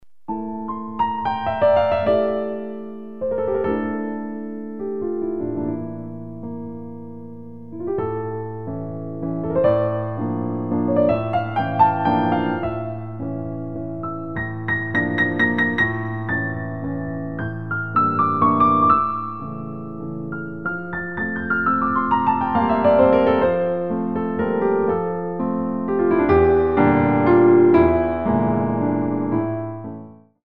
45 selections (67 minutes) of Original Piano Music